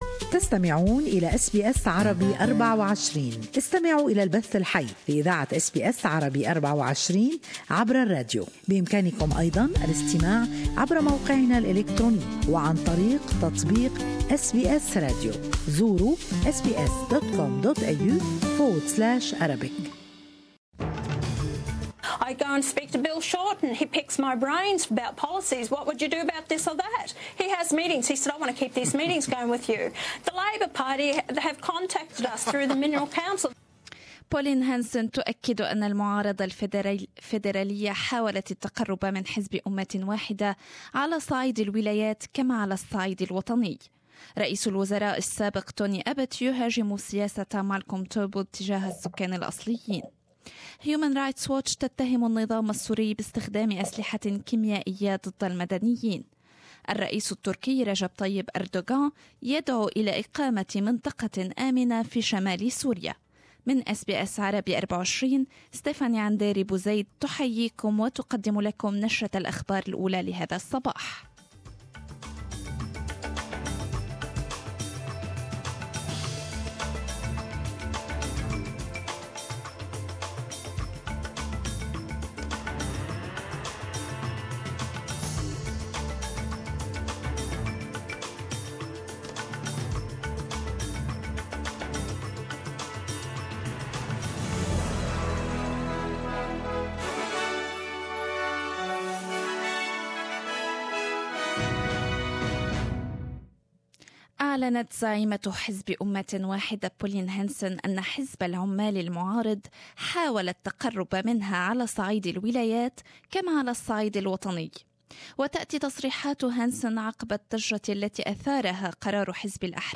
News Bulletin